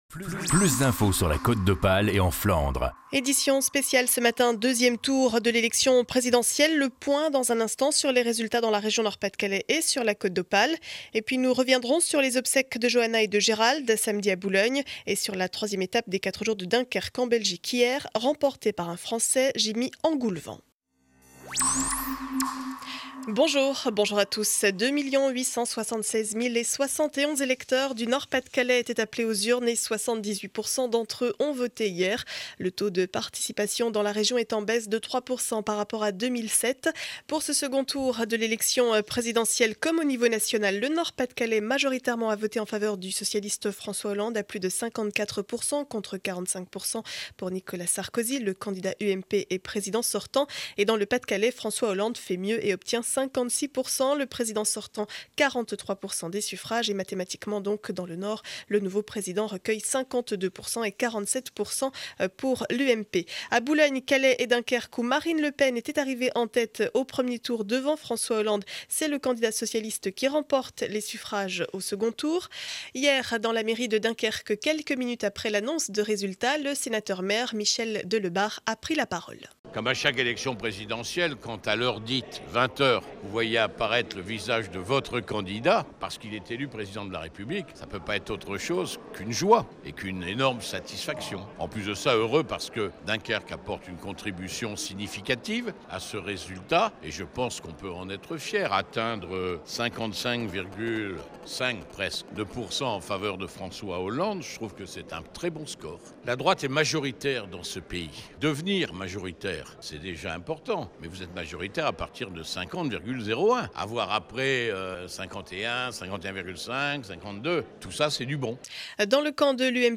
Journal du lundi 07 mai 2012 - édition spéciale 6 heures.